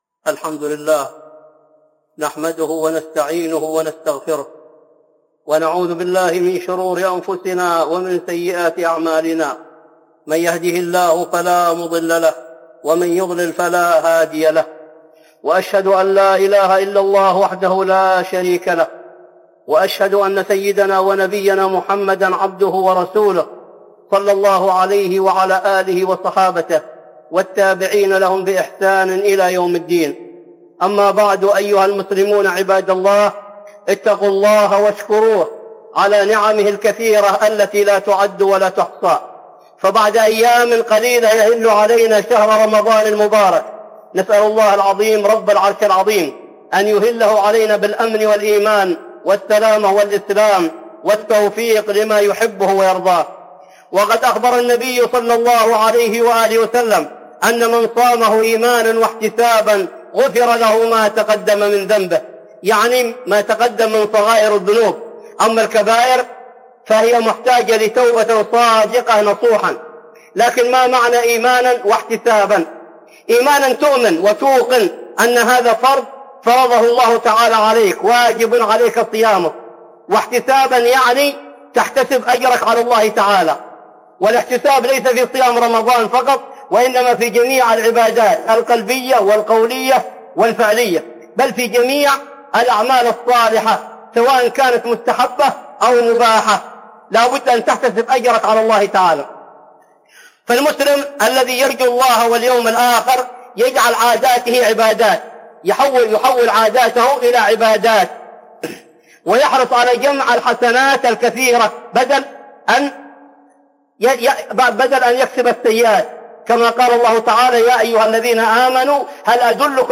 استمع إلى خطبة صوتية بعنوان "
خطبة جمعة بعنوان